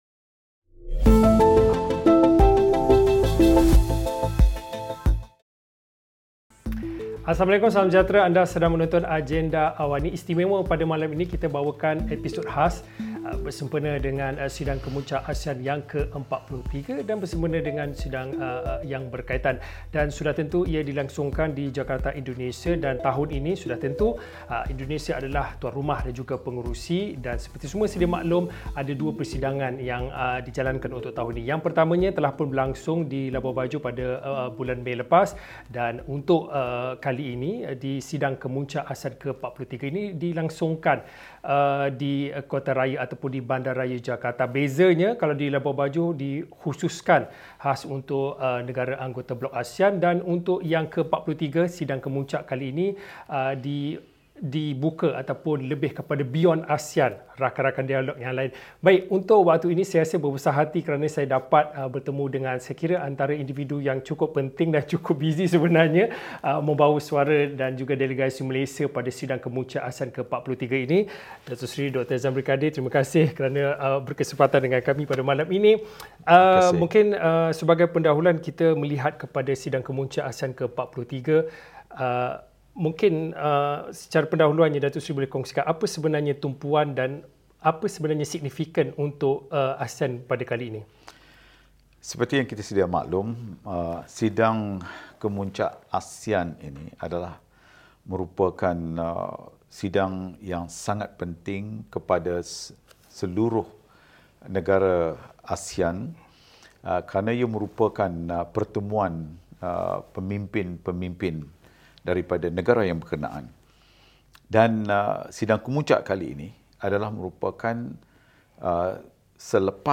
Sejauh mana kekuatan dan kesepaduan negara-negara anggota ASEAN dalam berkongsi idea dan jalan penyelesaian terhadap konflik dan cabaran yang beri kesan di peringkat serantau dan antarabangsa? Temu bual bersama Menteri Luar Negeri, Datuk Seri Dr. Zambry Abd Kadir 8.30 malam ini.